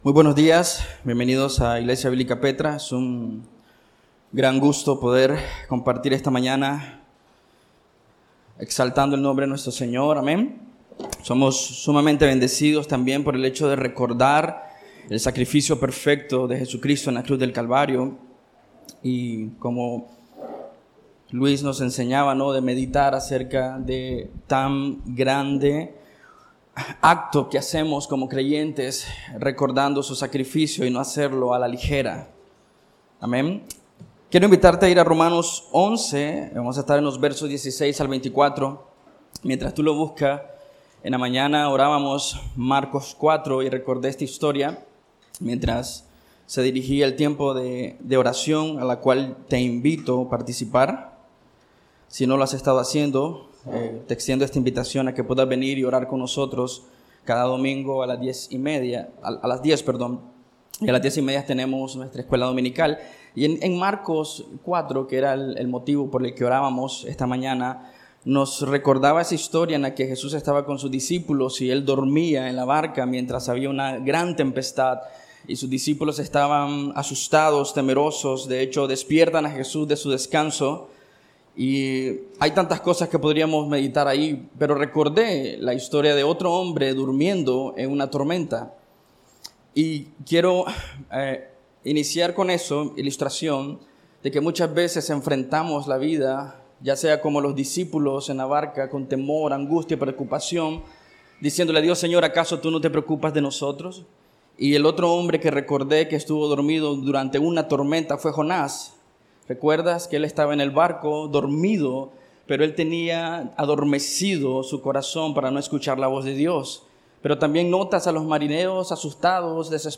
Escucha la prédica del domingo